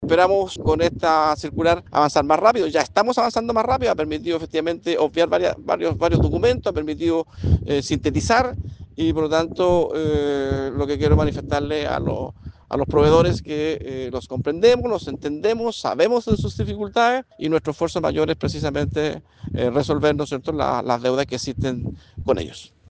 Consultado, el delegado de la provincia, Humberto Toro, señaló que el problema inicial fue la cantidad de documentos que debían presentar los proveedores, algo que se mejoró hace unas semanas a través de una circular que rebajó los requisitos para justificar o probar la prestación de los servicios.